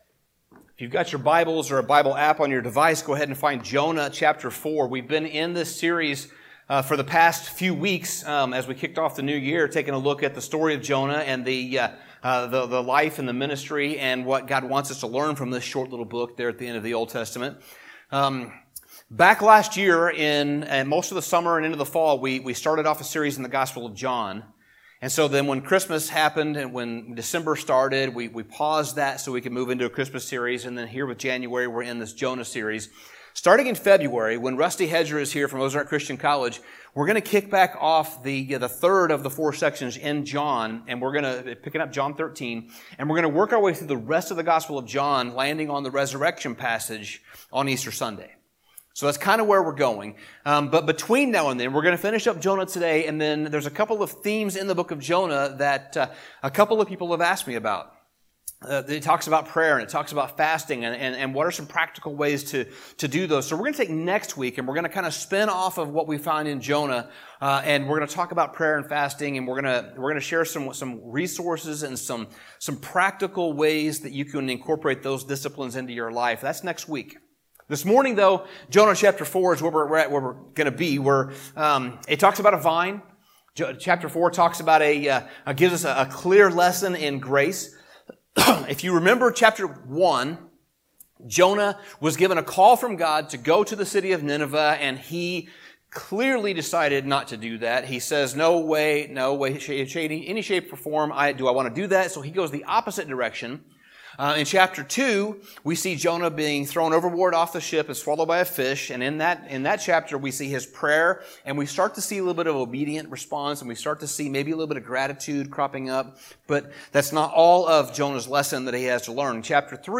Sermon Summary The final chapter of Jonah shows us a lesson in God's grace and mercy. Jonah responds poorly to the repentance of the Ninevites, and God teaches him a lesson through the rapid growth and then death of a shady vine.